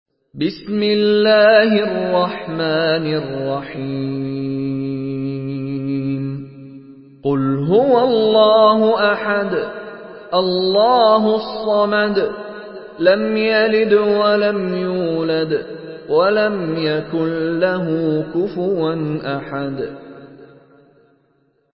Surah Al-Ikhlas MP3 by Mishary Rashid Alafasy in Hafs An Asim narration.
Murattal Hafs An Asim